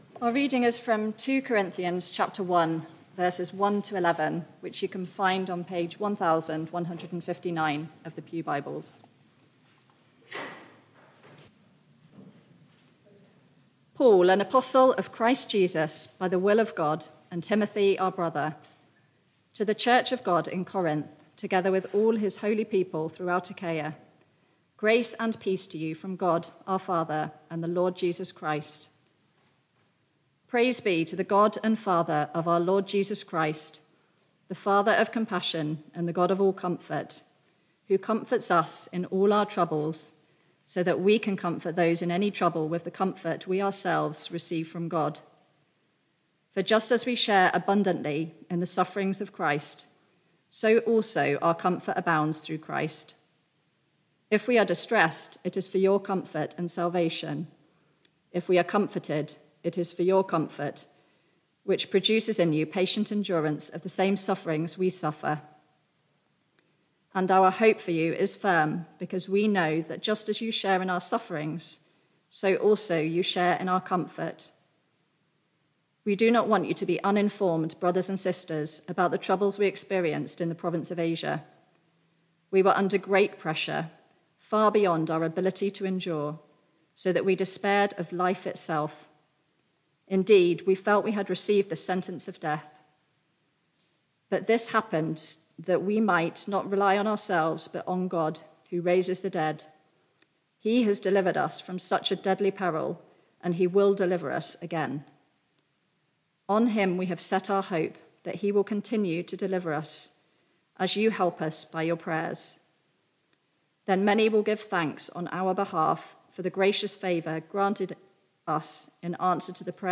Media for Barkham Morning Service on Sun 10th Sep 2023 10:00
Sermon